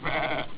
Pecora
pecora.wav